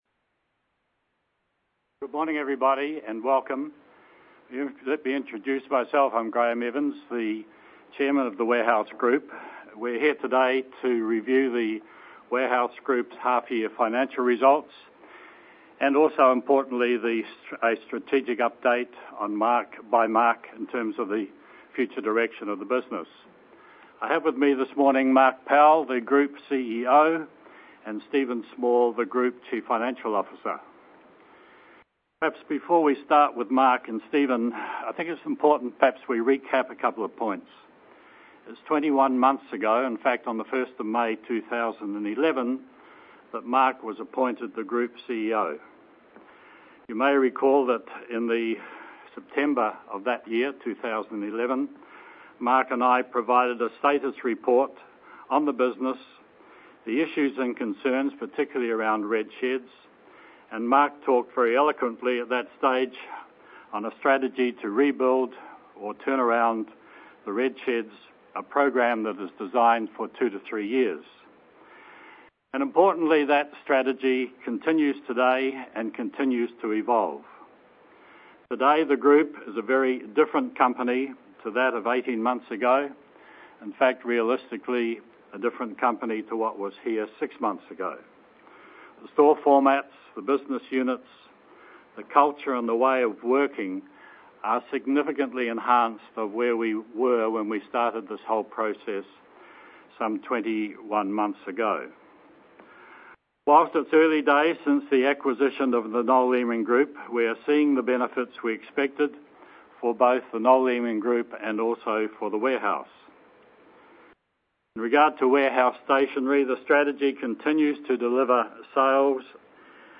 Audio recording of 2013 Interim Result teleconference